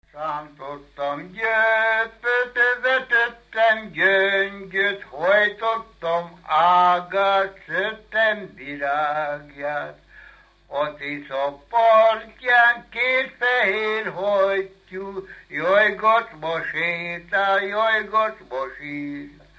Alföld - Pest-Pilis-Solt-Kiskun vm. - Kiskunhalas
Műfaj: Párosító
Stílus: 7. Régies kisambitusú dallamok
Kadencia: X (X) X 1